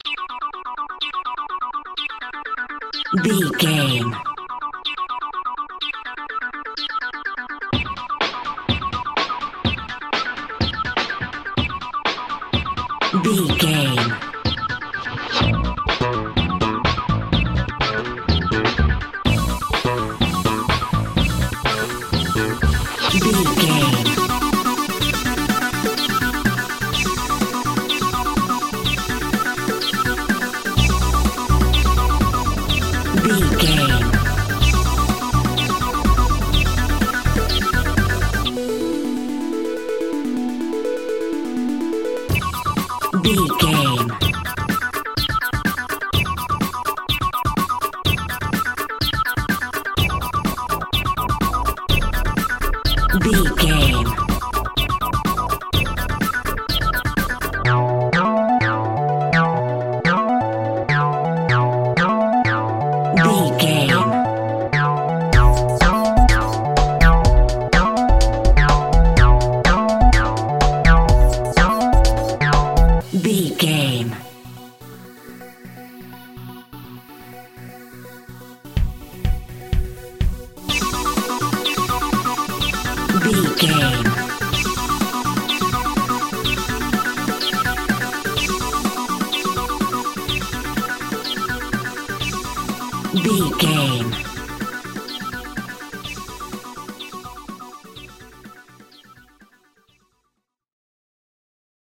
Flight Simulation Music.
Aeolian/Minor
electronic
techno
trance
synthesizer
drone
glitch
synth lead
synth bass